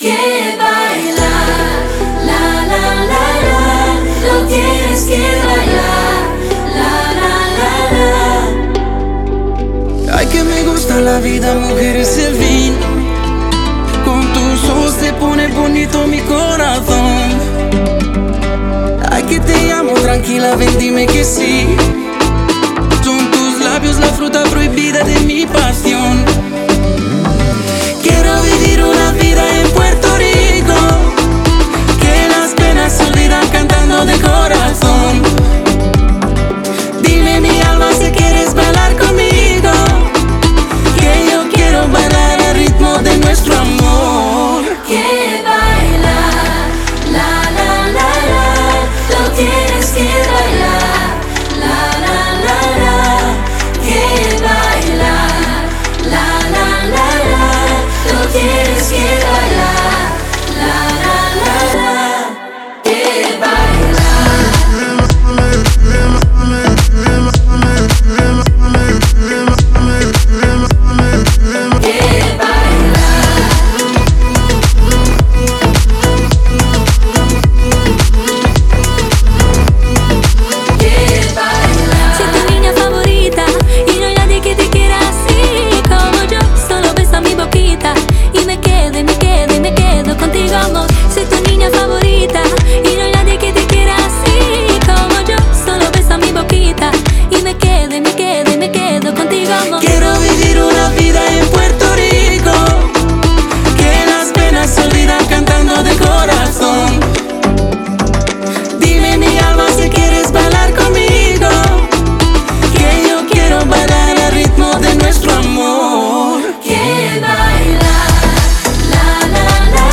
это зажигательная танцевальная композиция в жанре латин-поп